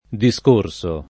vai all'elenco alfabetico delle voci ingrandisci il carattere 100% rimpicciolisci il carattere stampa invia tramite posta elettronica codividi su Facebook discorso [ di S k 1 r S o ] part. pass. di discorrere e s. m.